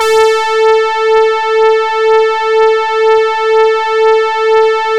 PROPHET 5 00.wav